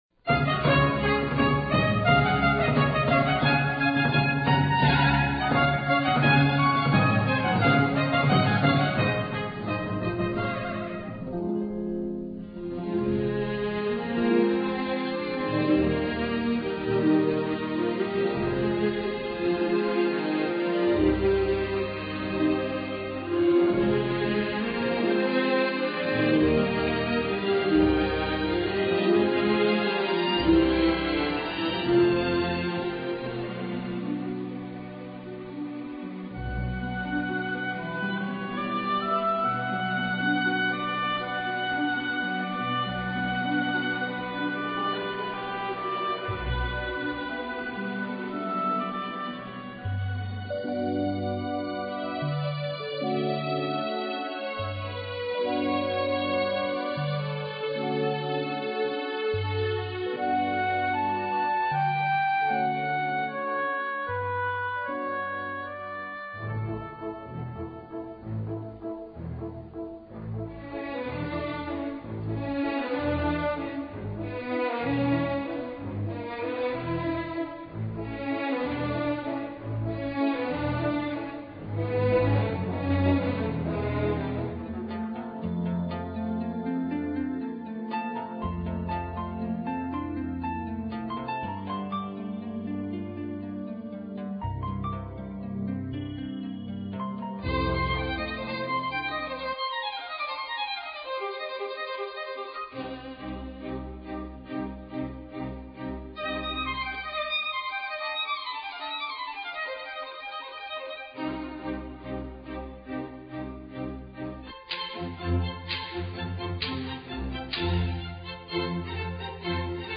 Instrumental.